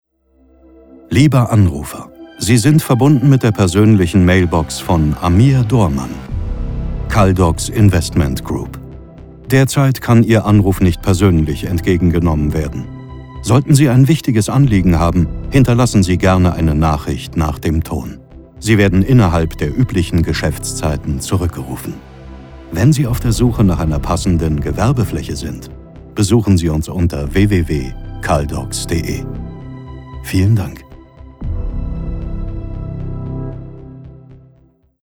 Telefonansagen mit echten Stimmen – keine KI !!!
Kaldox Management: Mailboxansage mit der deutschen Synchronstimme von Christian Bale & Johnny Depp